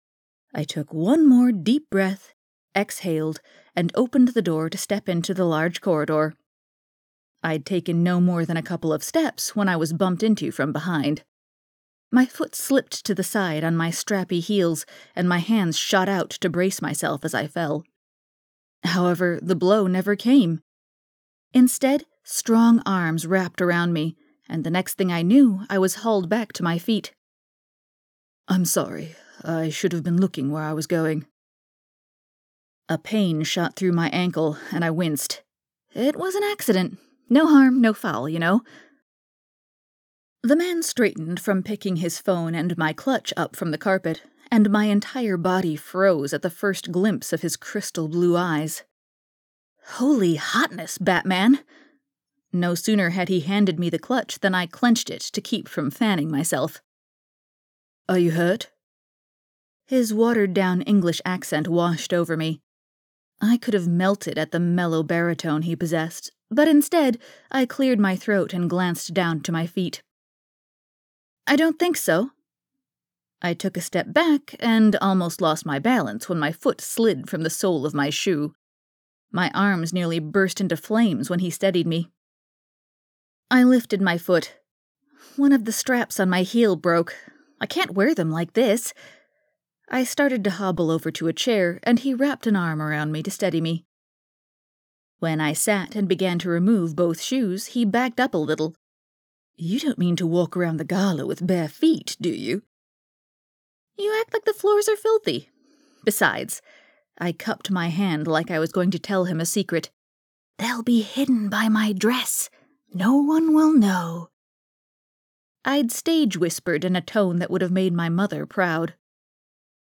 New Audiobook Release – A Novel Holiday
I liked it alot – Liz has such an “innocent” voice 😁
I loved the book and the narration sounds really good – `I loved the Darcy voice.
Nice intonation from the audio clip I was able to sample. Seems like the narrator will be able to do a pretty decent range from Liz to Will with accents too!